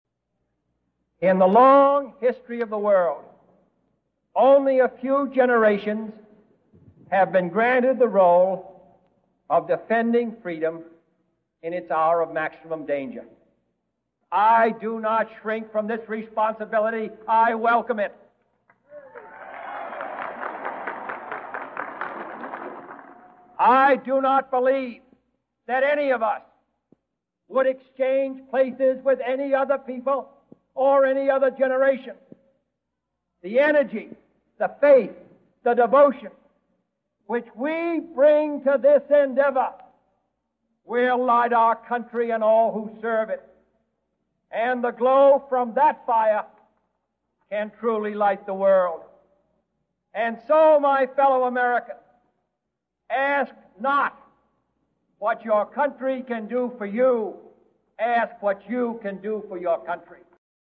英文講稿 X 原音重現，帶你回到改變的歷史現場。
就職演說，一九六一年一月二十日，華盛頓